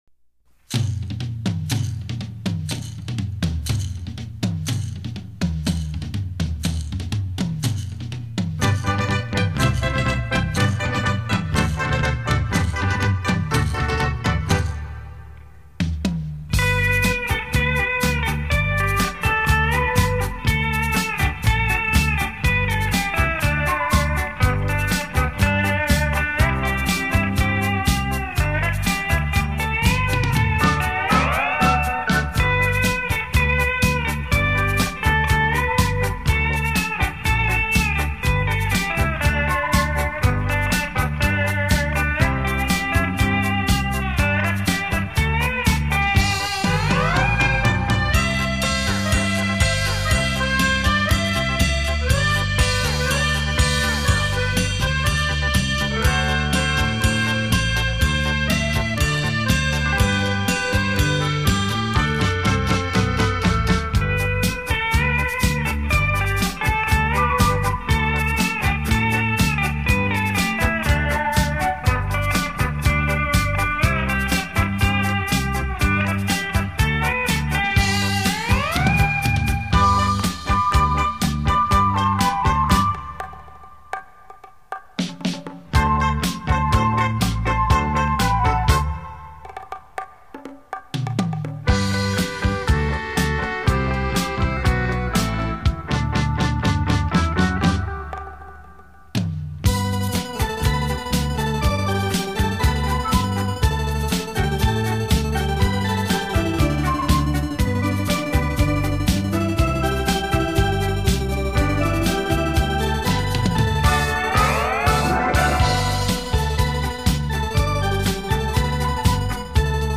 专辑类型：电子琴音乐
超立体现场演奏
曲曲精選 曲曲動聽，电子琴音域较宽，和声丰富，